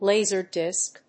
アクセントláser dìsk